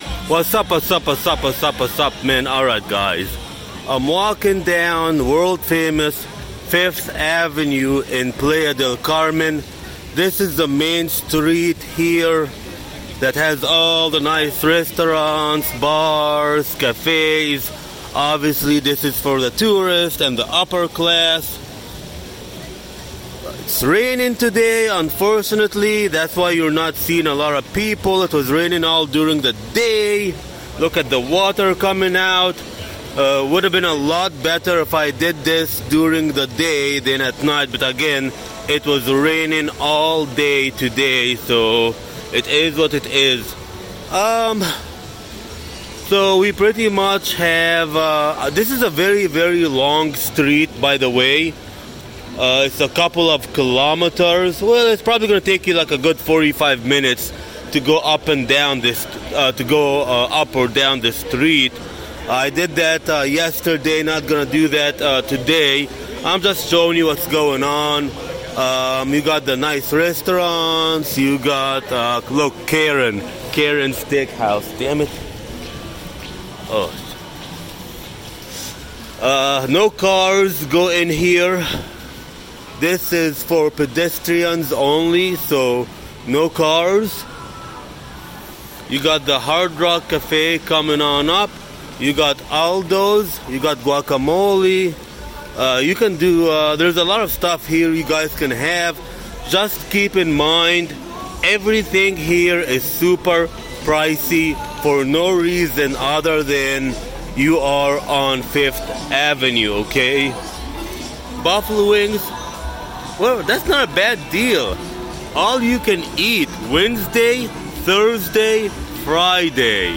Walking Around World Famous 5th Ave In Playa Del Carmen